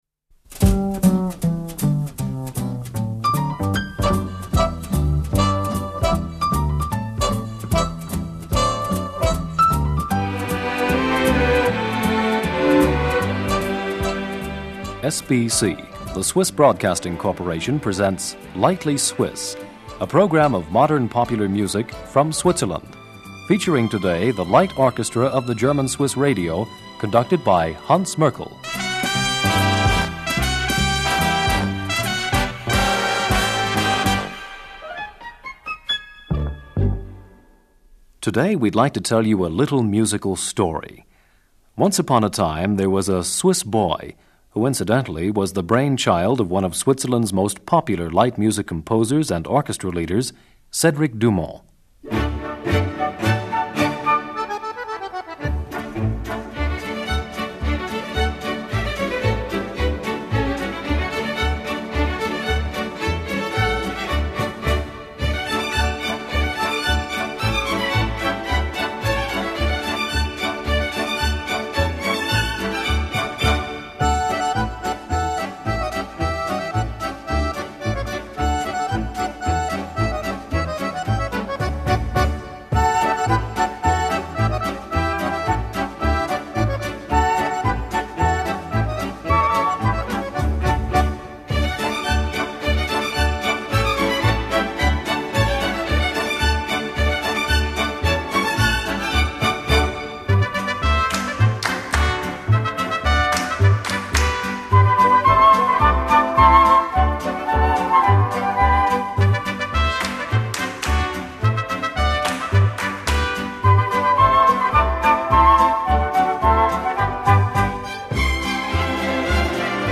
vocal. 3.